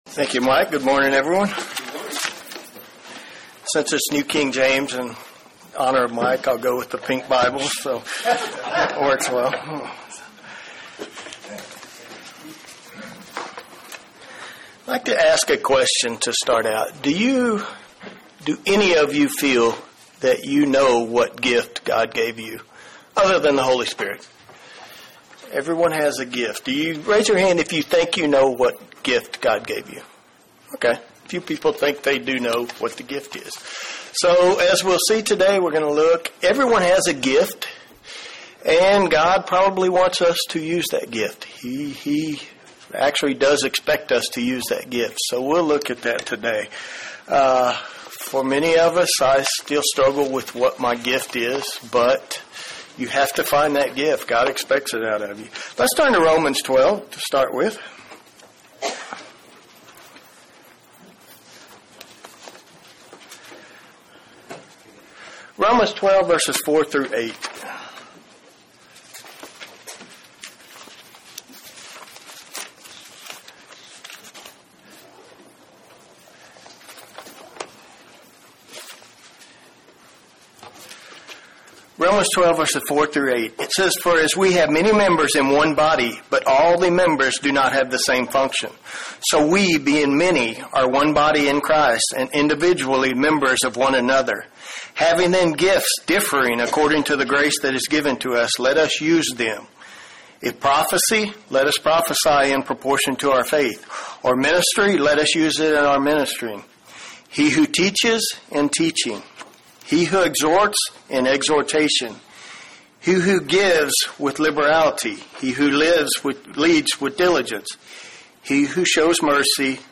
UCG Sermon Studying the bible?
Given in Murfreesboro, TN